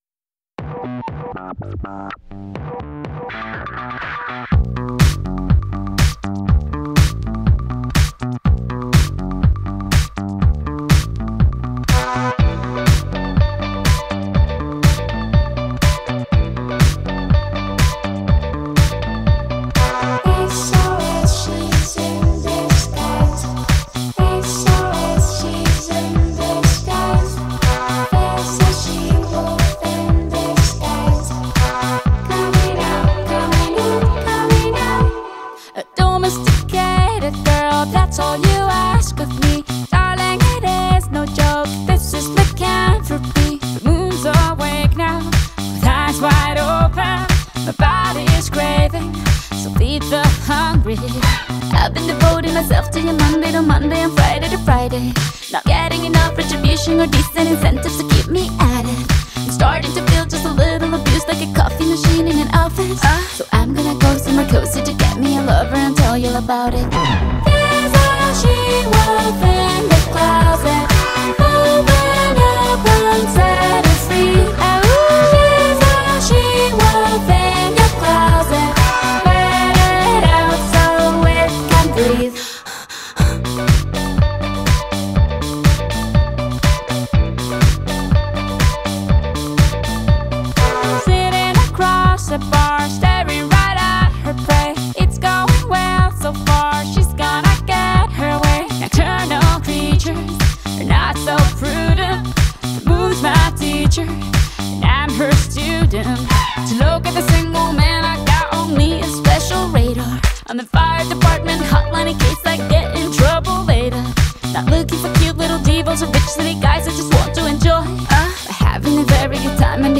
Pop, Dance-Pop, Latin Pop